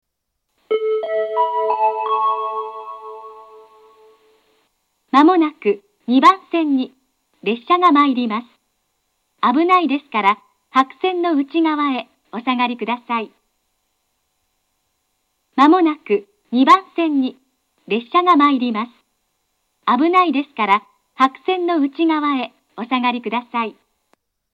接近放送は首都圏でもよく聞ける東海道型の放送です。
２番線接近放送
shirakawa-2bannsenn-sekkinn.mp3